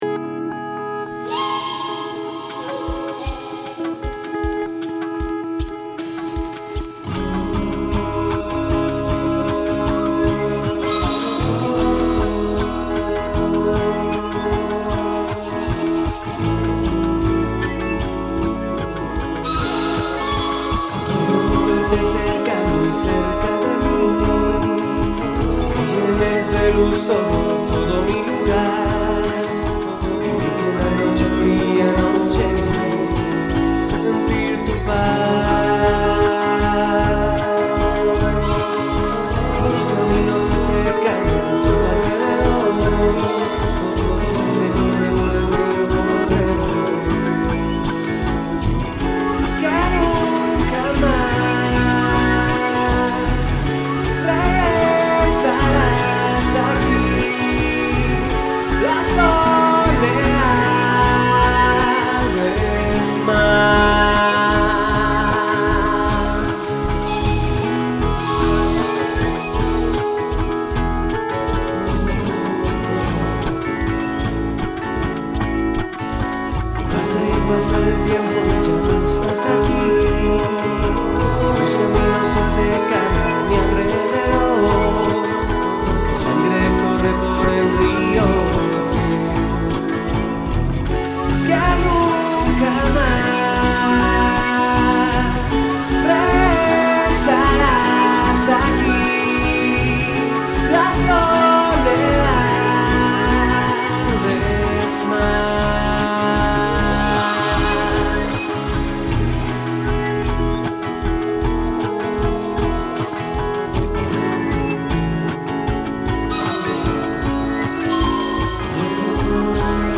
Paisaje Sonoro, Identidad Cultural, Patrimonio Ancestral, Conciencia y Recursos